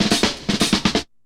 Drum roll 3.wav